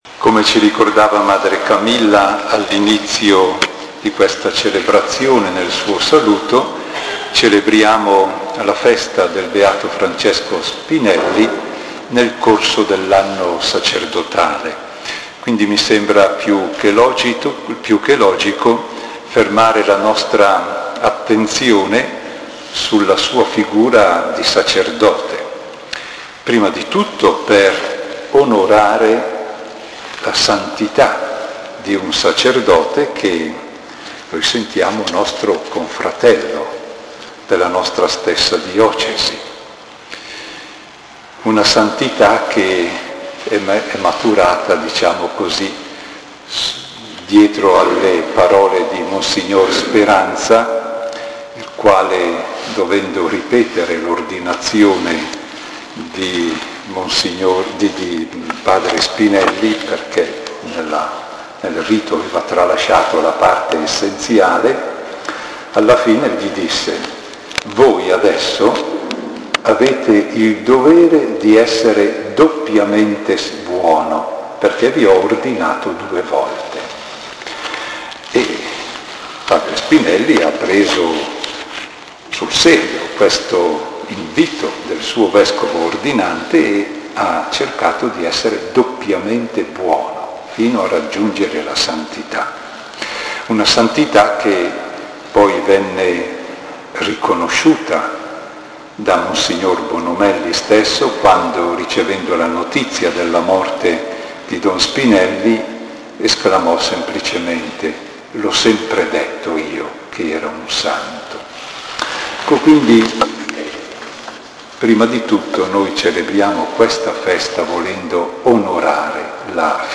Omelia di mons. Dante Lafranconi Vescovo di Cremona
Sacramento Rivolta d’Adda CR – 6 febbraio 2010 Messa nella festa liturgica del Beato Francesco Spinelli Leggi Ascolta Omelia beato Spinelli